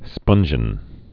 (spŭnjĭn)